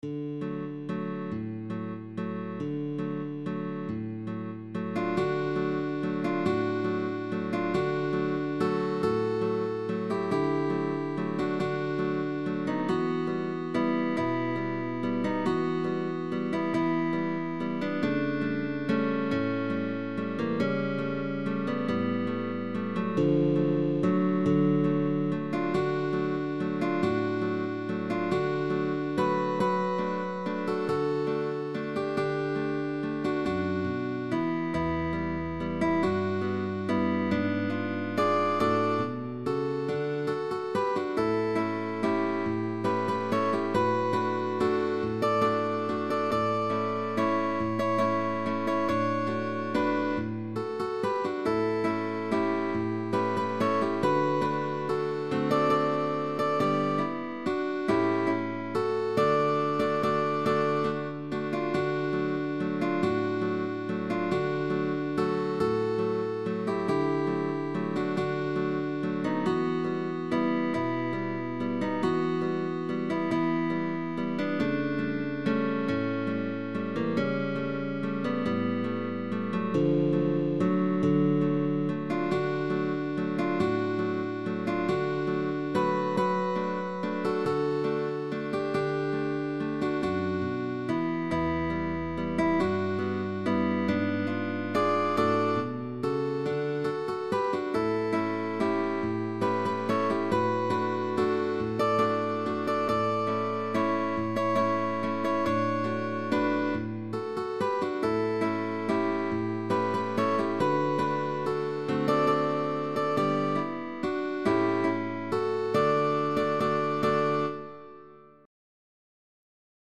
Guitar trio sheetmusic.
GUITAR TRIO